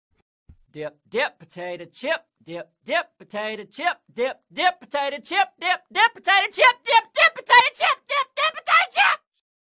Play, download and share BIBIBOBOB original sound button!!!!
dip-dip-potato-chip-sound-effect_WiymXNT.mp3